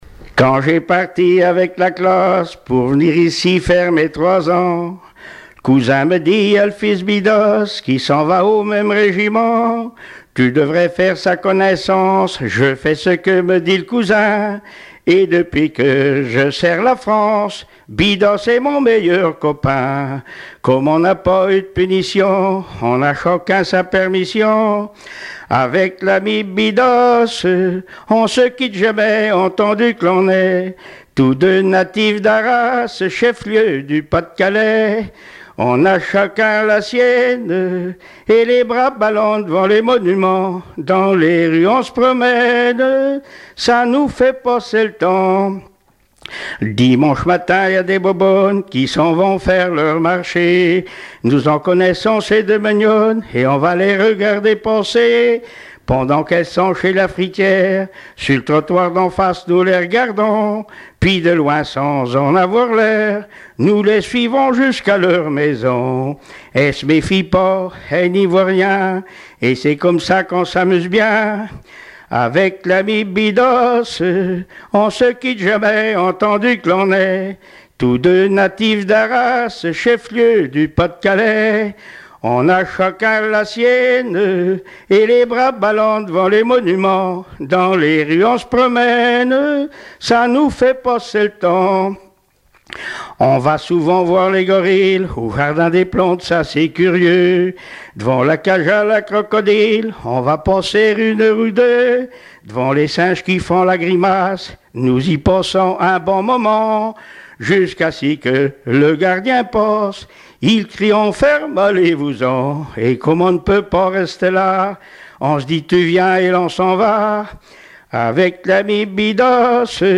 Genre strophique
Témoignages et chansons traditionnelles et populaires
Pièce musicale inédite